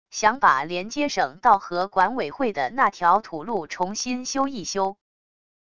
想把连接省道和管委会的那条土路重新修一修wav音频生成系统WAV Audio Player